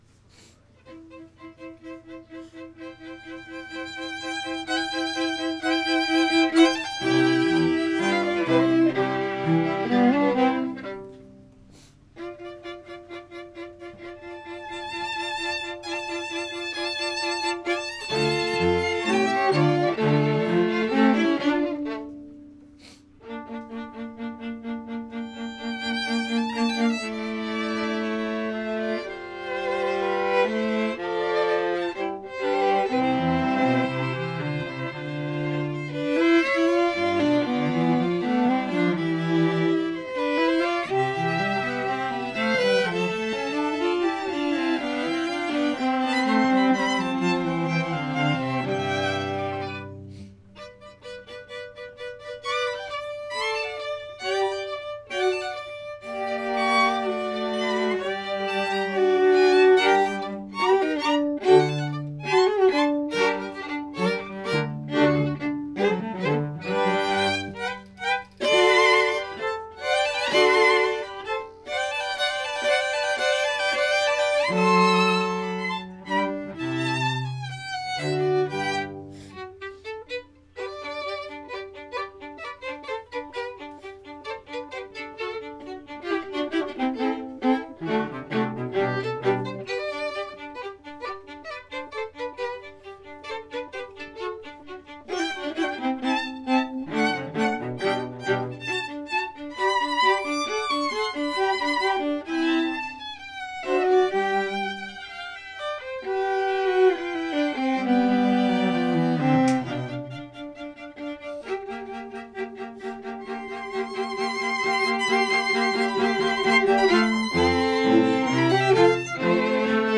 2:00 PM on July 20, 2014, "Music with a View"
Chamber Groups
Allegro moderato